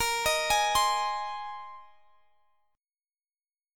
Bb7sus2sus4 Chord
Listen to Bb7sus2sus4 strummed